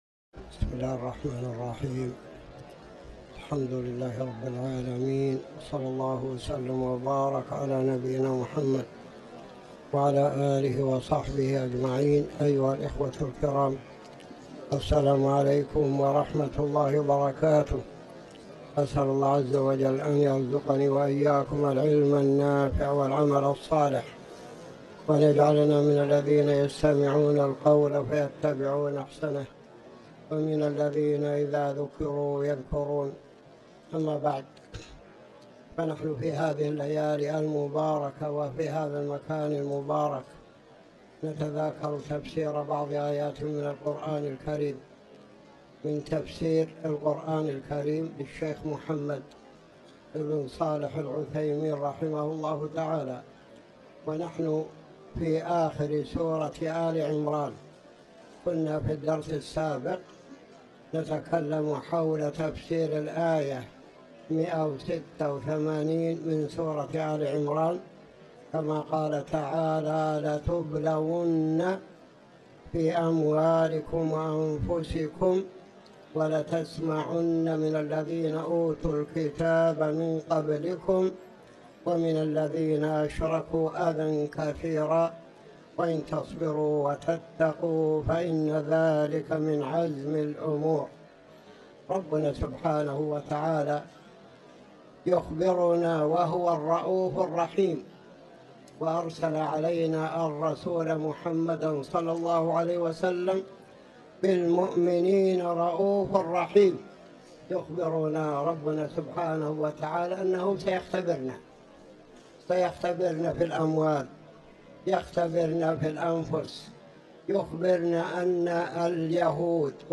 تاريخ النشر ١٦ رجب ١٤٤٠ هـ المكان: المسجد الحرام الشيخ